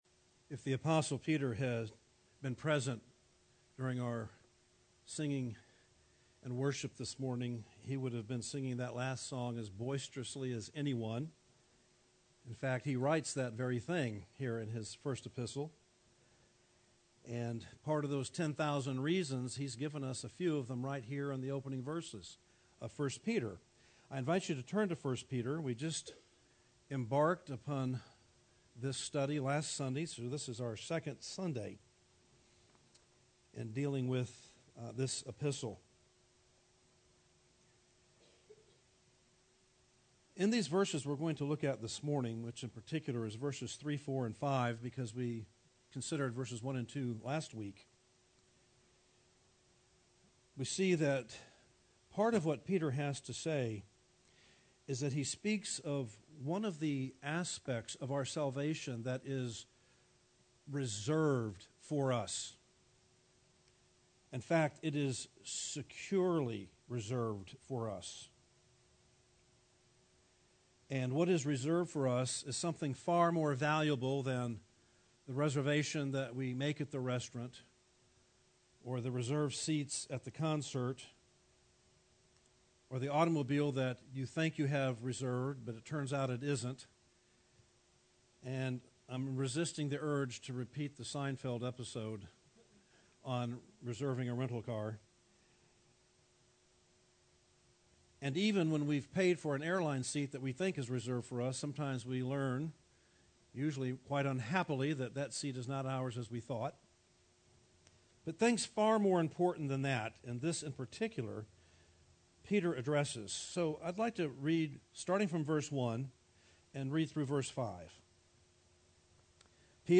teaches from the series: I Peter, in the book of 1 Peter, verses 1:1 - 1:5